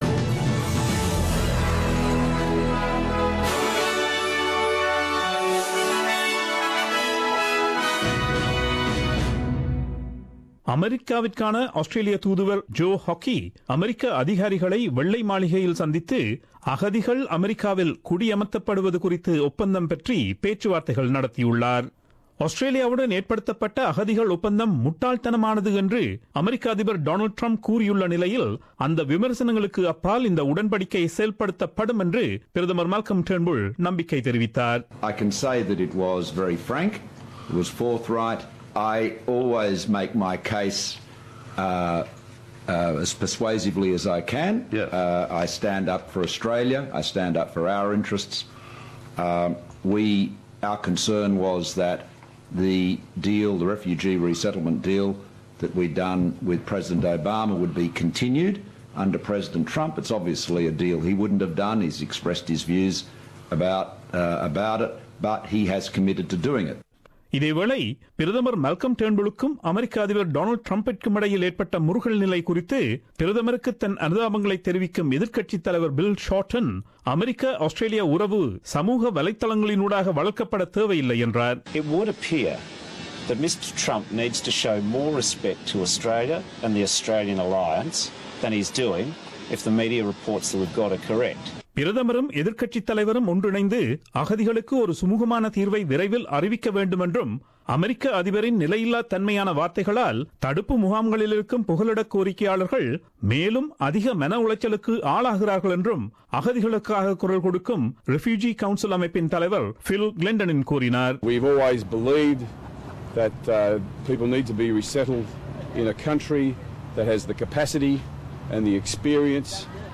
Australian news bulletin aired on Friday 03 Feb 2017 at 8pm.